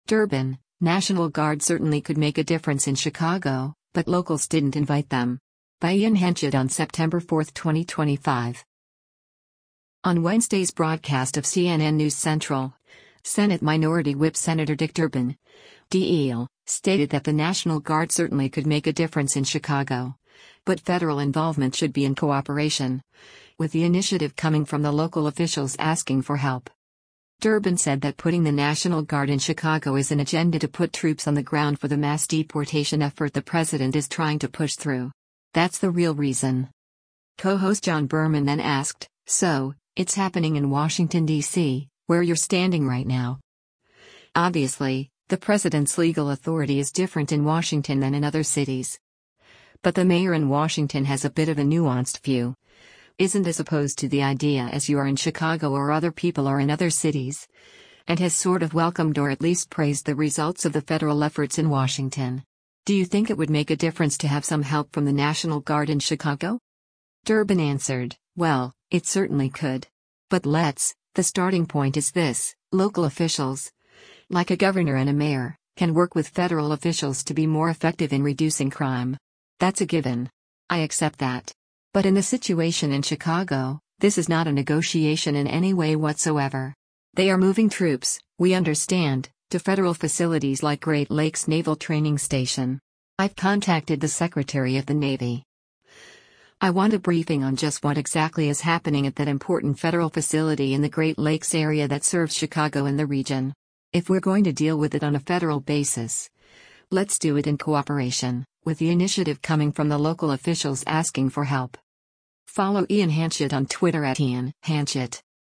On Wednesday’s broadcast of “CNN News Central,” Senate Minority Whip Sen. Dick Durbin (D-IL) stated that the National Guard “certainly could” make a difference in Chicago, but federal involvement should be “in cooperation, with the initiative coming from the local officials asking for help.”